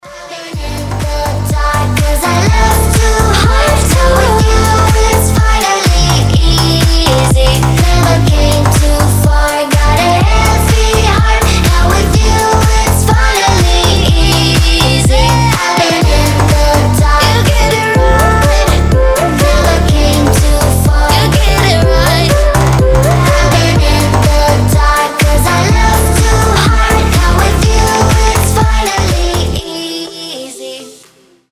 • Качество: 320, Stereo
громкие
зажигательные
future house
басы
детский голос